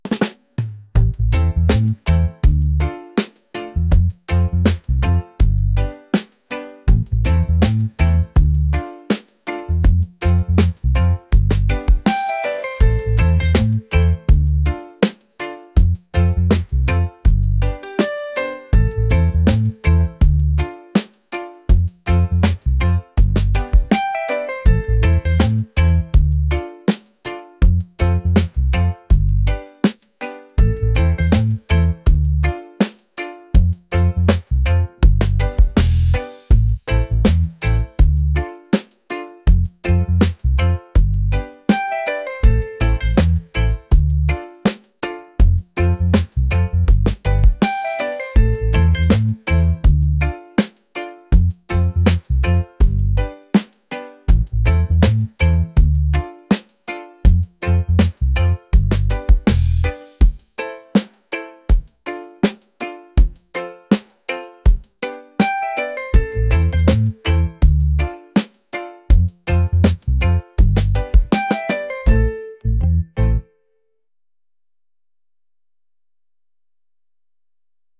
laid-back | smooth | reggae